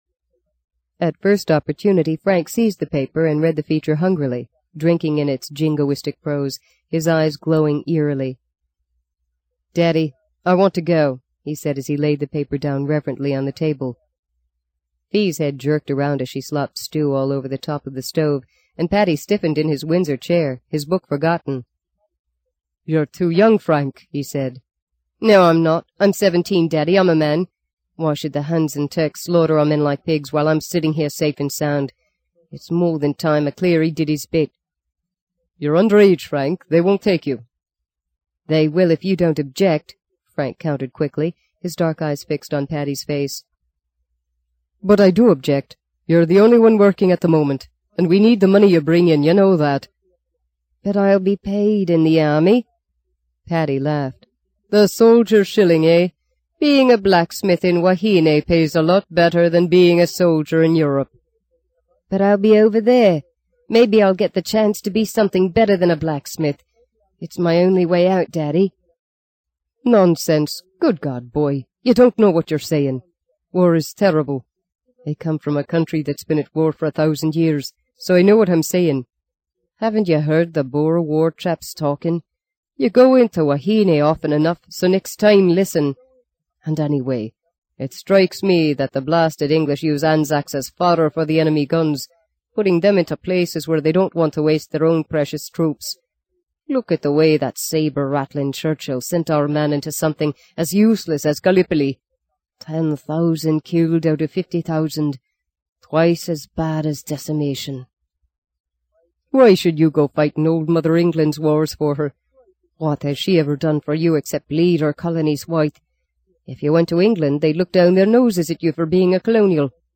在线英语听力室【荆棘鸟】第二章 24的听力文件下载,荆棘鸟—双语有声读物—听力教程—英语听力—在线英语听力室